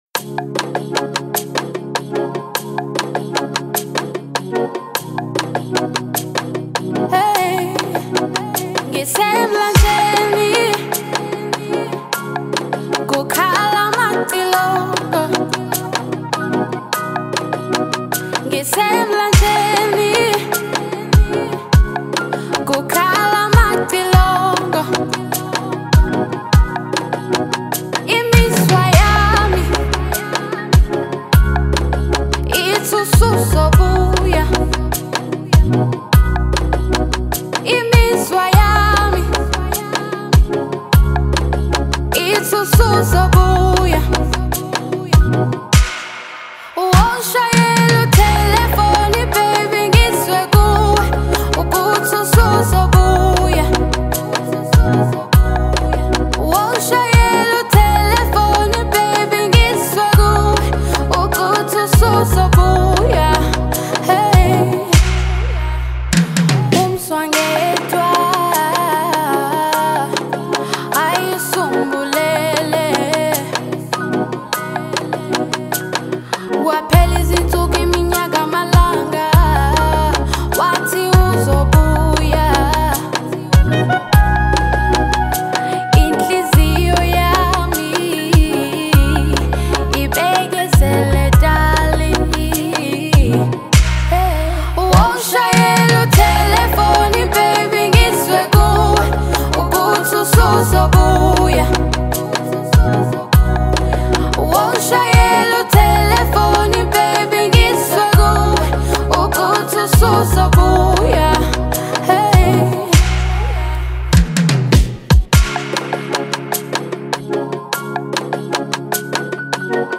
a perfect blend of catchy pop and heartfelt lyrics